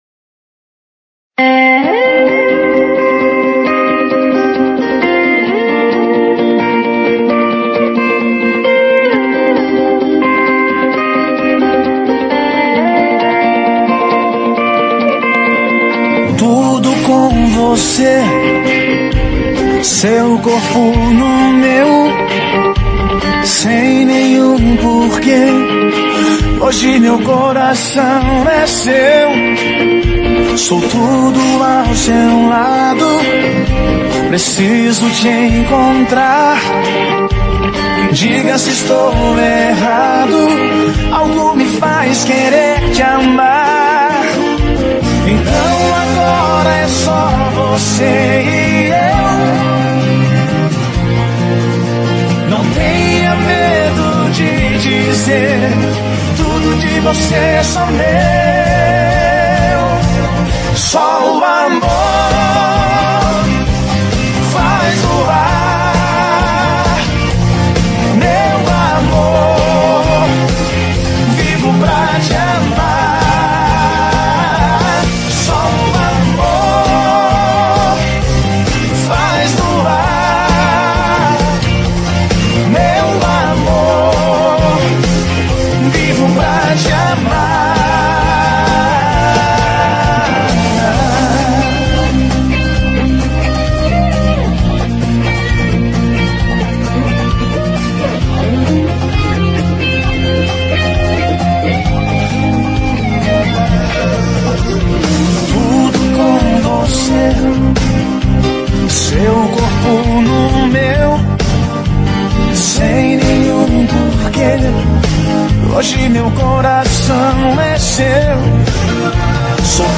Sertanejas Para Ouvir: Clik na Musica.